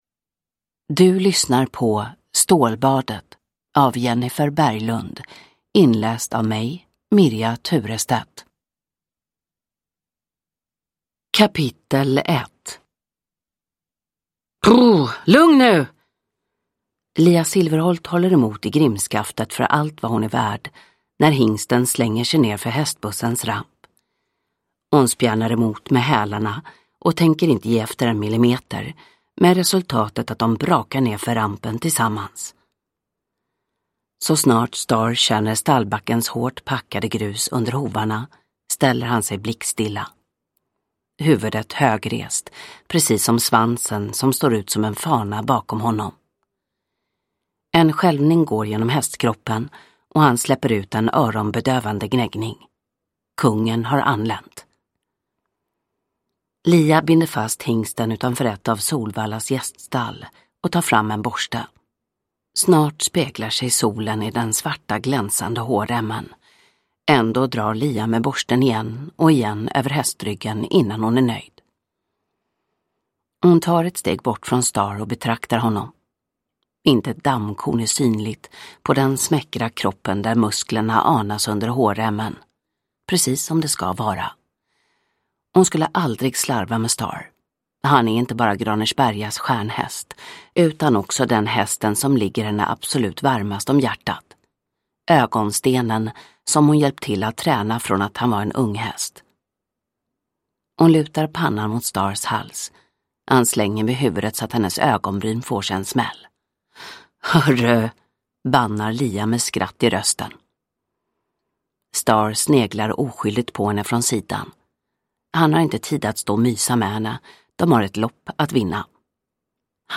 Stålbadet – Ljudbok
Uppläsare: Mirja Turestedt